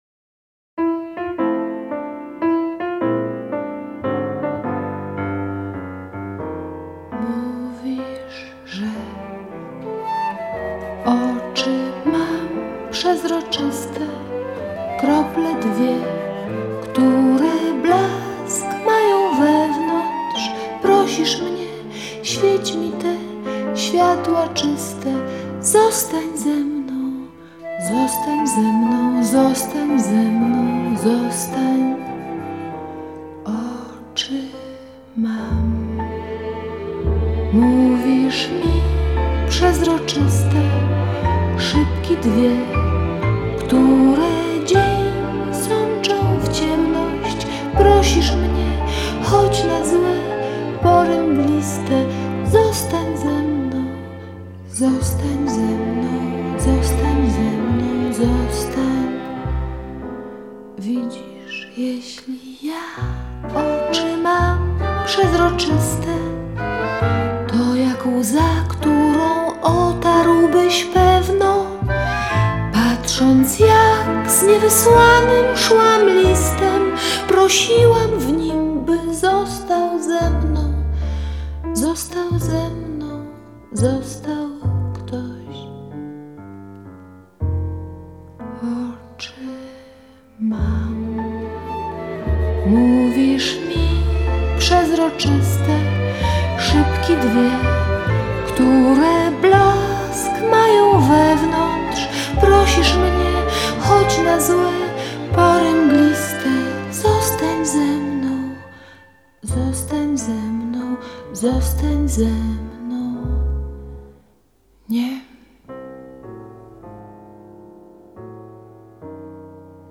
koncert